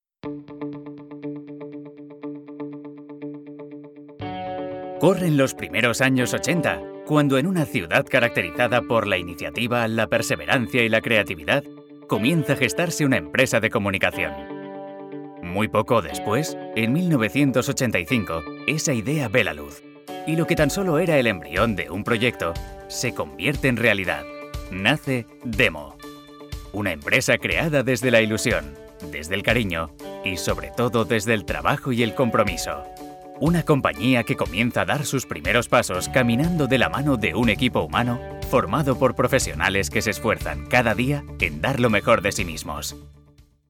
Mi voz es amable. Tengo un amplio abanico de registros: serio, gracioso, institucional...
kastilisch
Sprechprobe: Industrie (Muttersprache):